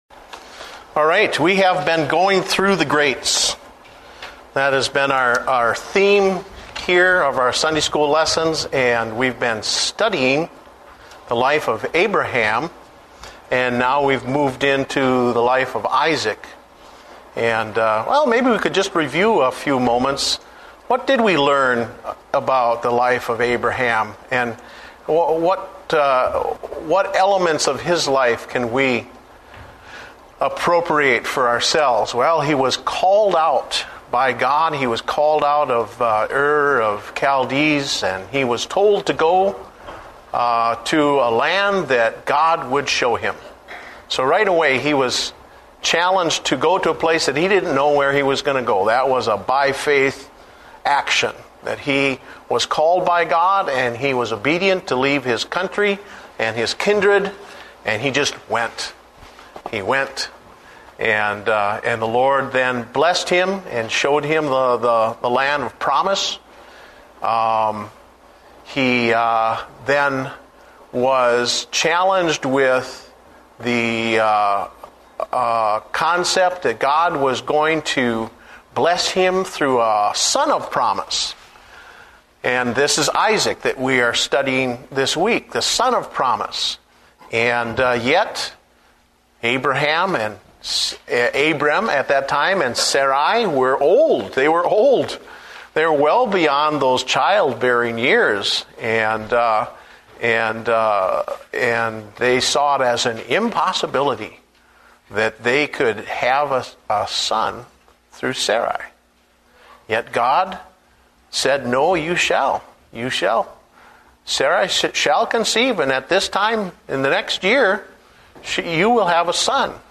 Date: March 1, 2009 (Adult Sunday School)